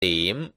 dim o'clock